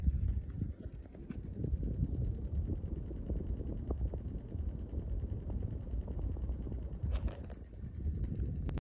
Je constate qu'il y a un bruit de "tac tac tac" continu sur la vidéo.
Je vais refaire un enregistrement avec mon téléphone en mode Dictaphone, ainsi qu'avec un split qui n'a pas ce bruit.
Et le FTXM25 de la chambre qui présente le bruit type tac tac tac...
split-chambre-ftxm25.mp3